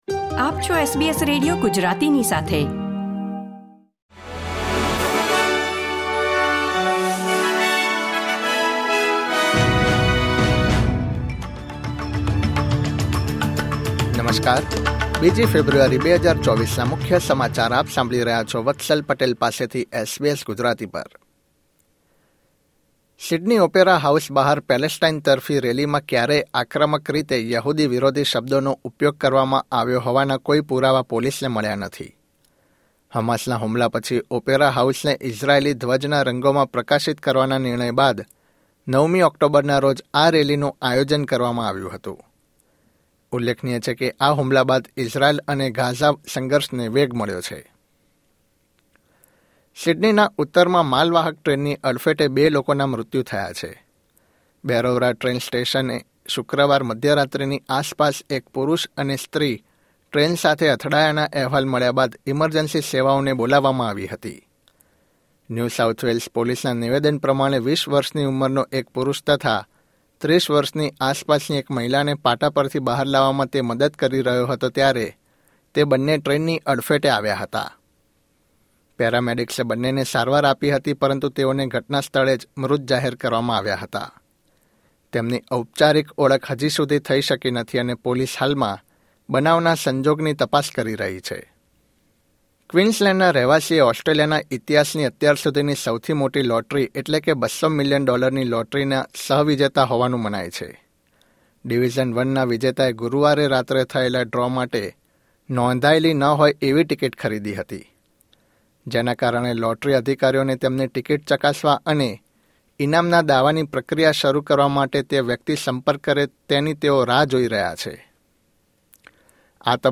SBS Gujarati News Bulletin 2 February 2024